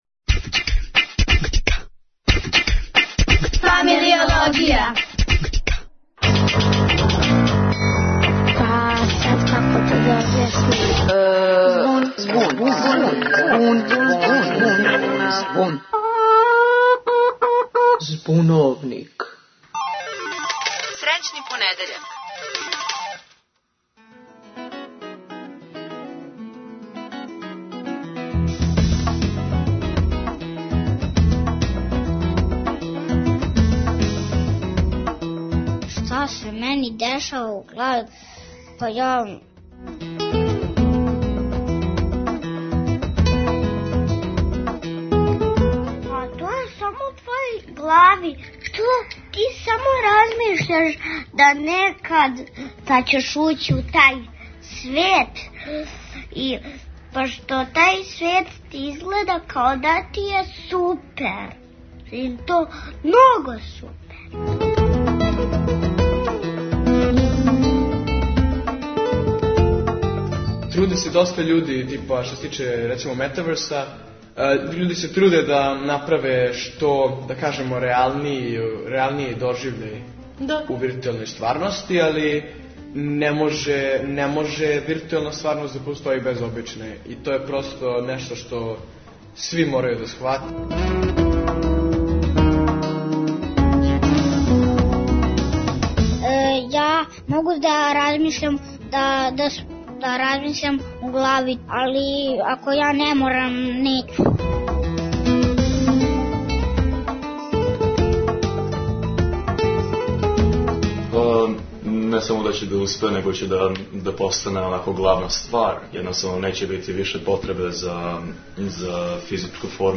Говоре деца, млади